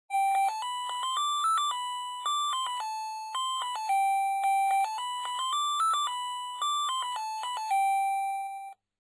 ZVONČEK 16 MELÓDIÍ
• elektronický
• zvuk: 16 striedajúcich sa druhov melódií